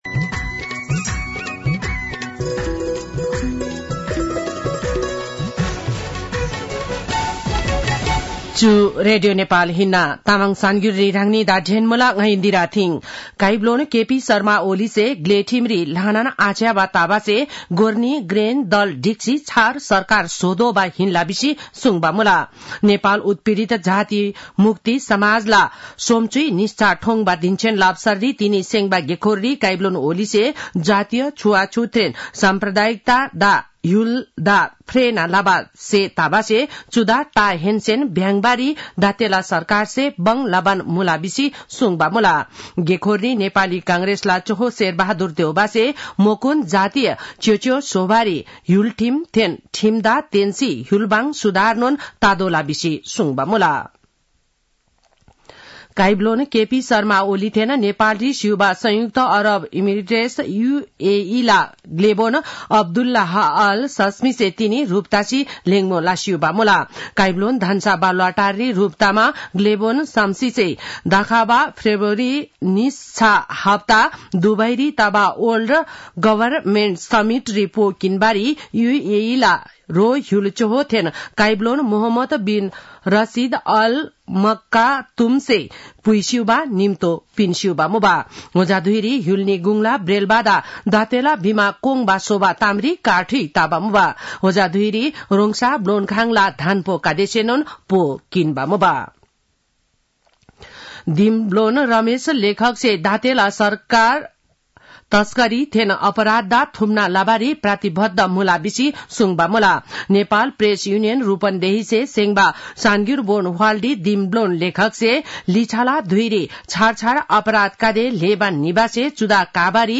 तामाङ भाषाको समाचार : ४ पुष , २०८१